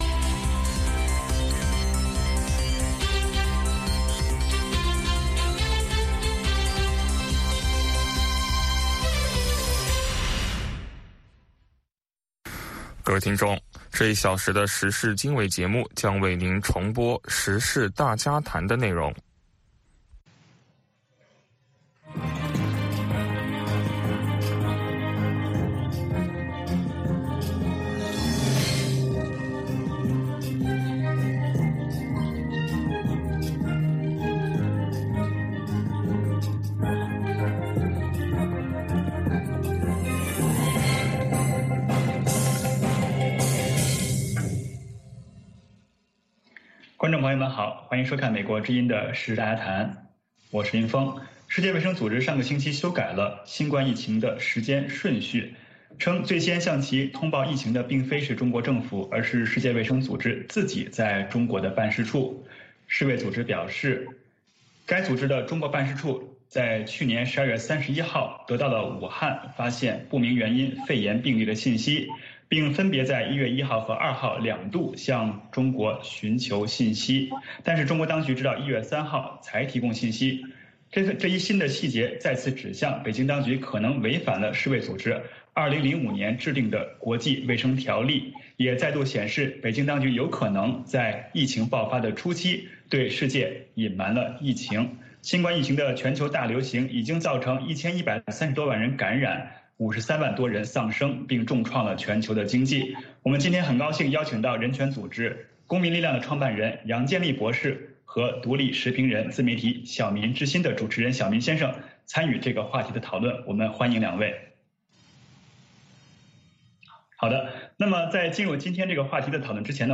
美国之音中文广播于北京时间每天晚上7-8点播出《时事经纬》节目。《时事经纬》重点报道美国、世界和中国、香港、台湾的新闻大事，内容包括美国之音驻世界各地记者的报道，其中有中文部记者和特约记者的采访报道，背景报道、世界报章杂志文章介绍以及新闻评论等等。